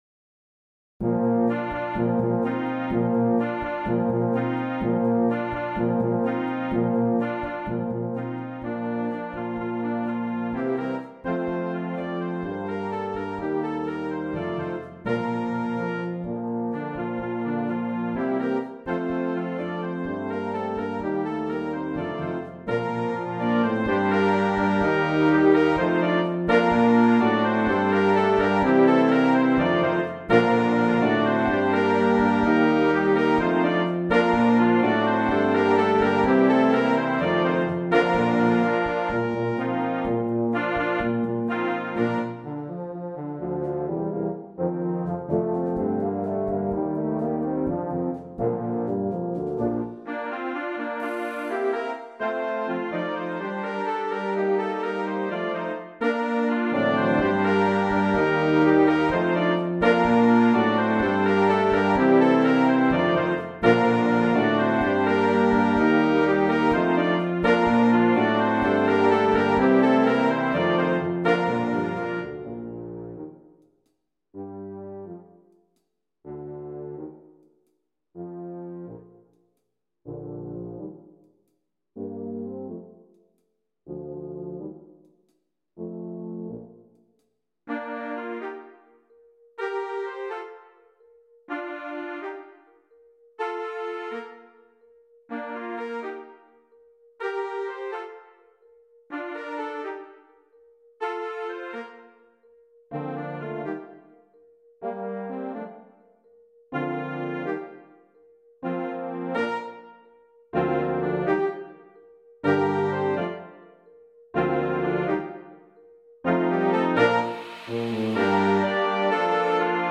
Gattung: für Jugendblasorchester
Stimme 1 in C (8va): Flöte
Stimme 1 in C: Oboe, Piccolo
Stimme 1 in B: 1. Klarinette, 1. Trompete / Cornet
Stimme 4 in F: Horn
Stimme 4 in Es: Es-Althorn, Altklarinette, Altsaxophon
Stimme 5 in C/B: 1. Posaune / Bariton
Stimme 7 in C: Euphonium
Stimme 8 in C: Tuba, Kontrabass, Fagott
Percussion